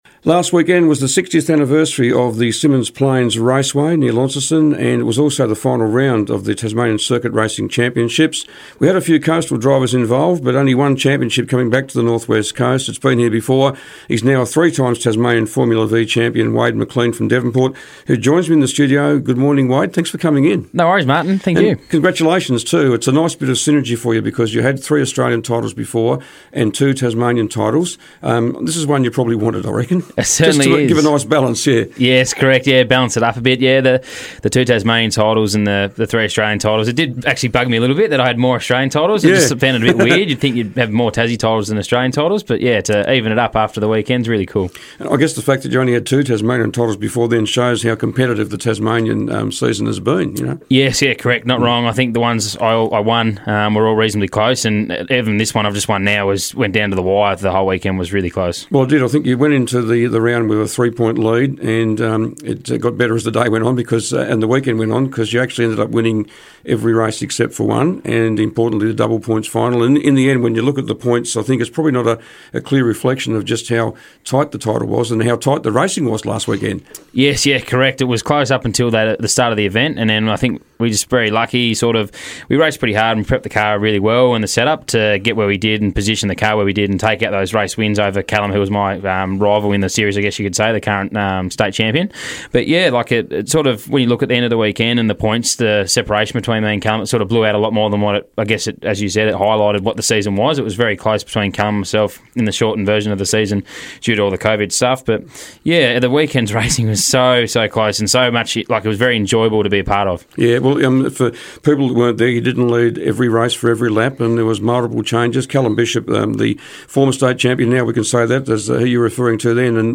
in the studio this morning